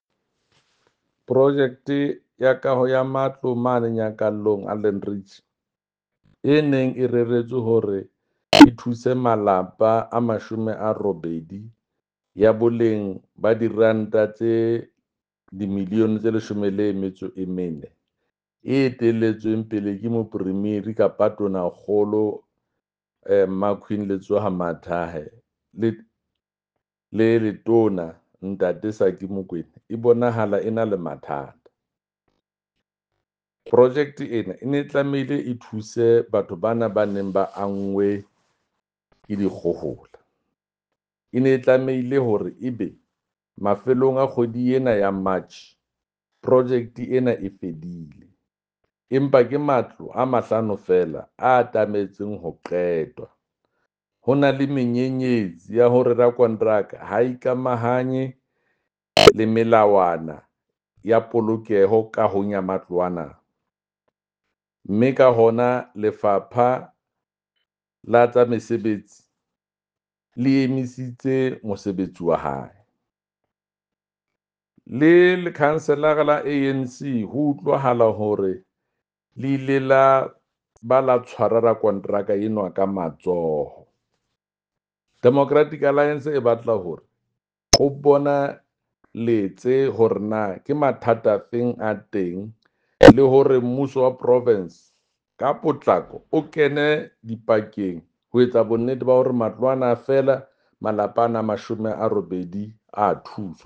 Sesotho soundbite by Jafta Mokoena MPL